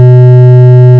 The sound these gravitational waves would produce is a continuous tone since the frequency of the gravitational wave is nearly constant.
Example Continuous Waveform
continuous.wav